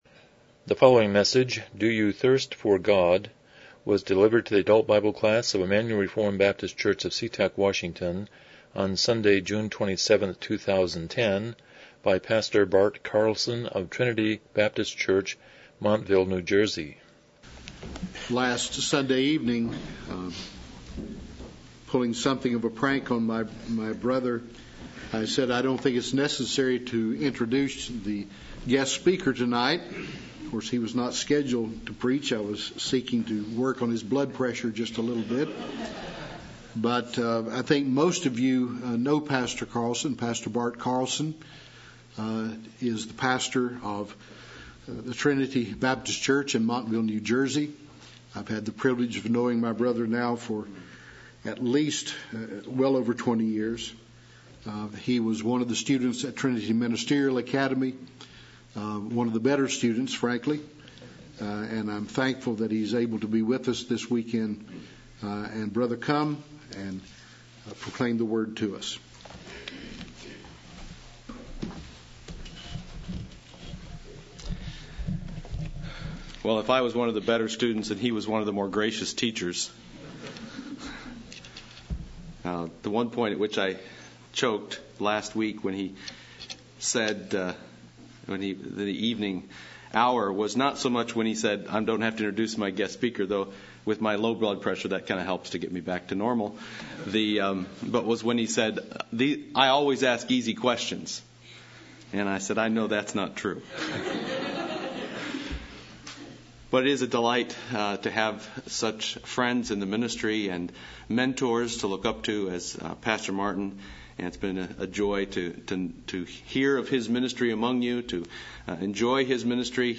Miscellaneous Service Type: Sunday School « 3 Philippians 1:3-7 God’s Grace to Grumblers